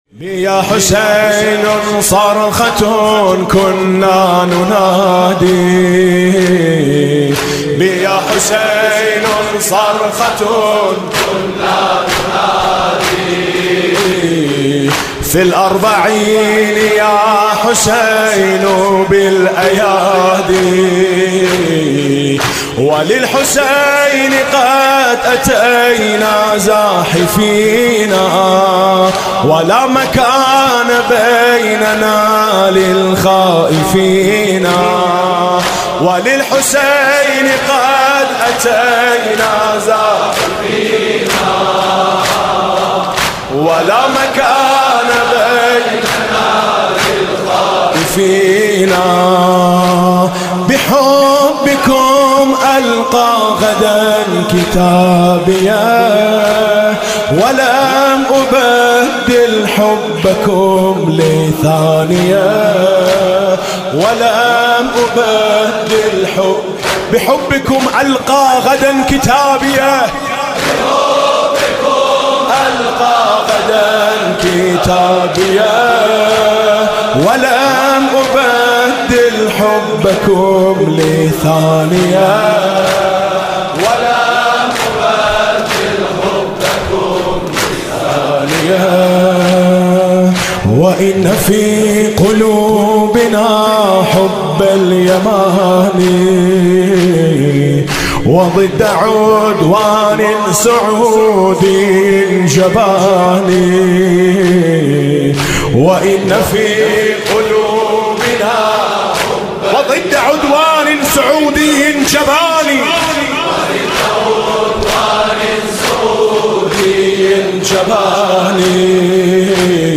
صوت/ مداحی میثم مطیعی علیه‌ آل‌سعود
میثم مطیعی شب گذشته در حمایت از مردم یمن و علیه آل‌سعود به زبان عربی و فارسی مداحی کرد.